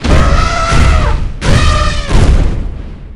elephant.wav